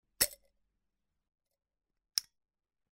Abrir y cerrar un tarro de azúcar
Sonidos: Acciones humanas
Sonidos: Hogar